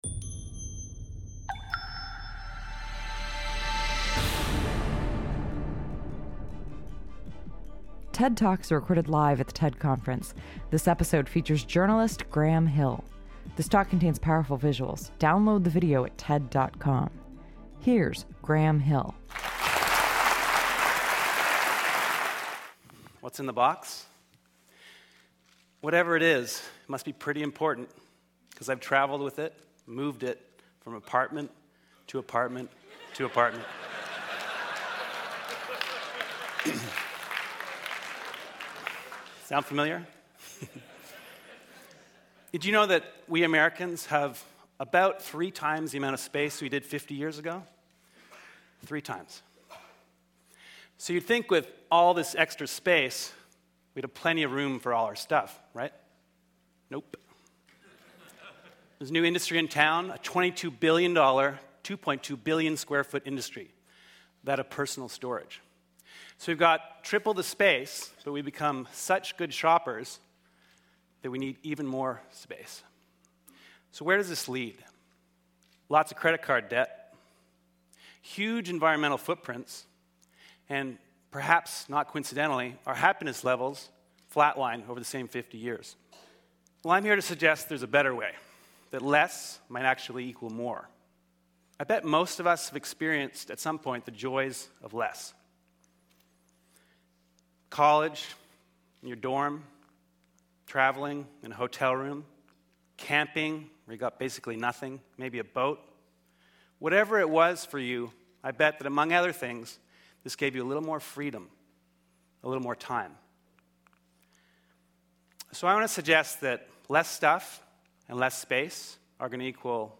TED Talks